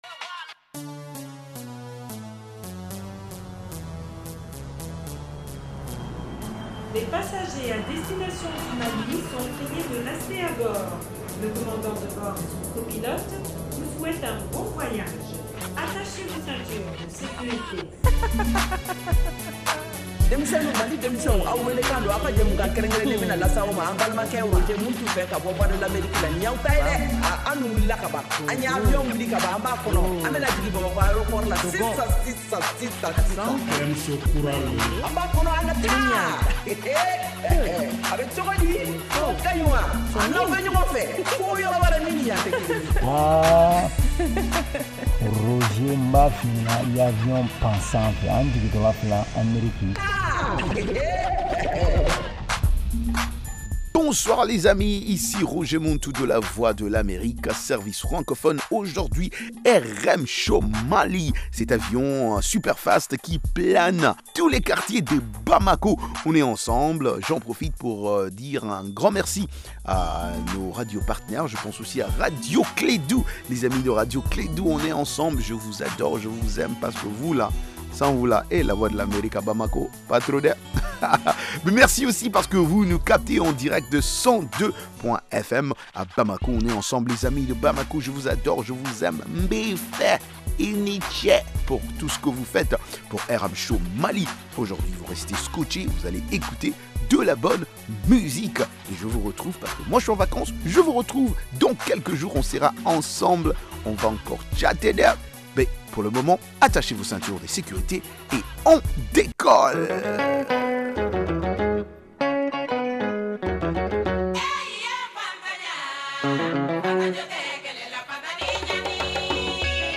propose une sélection spéciale de musique malienne et internationale. Participez aussi dans Micro-Mali pour debattre des sujets socio-culturels.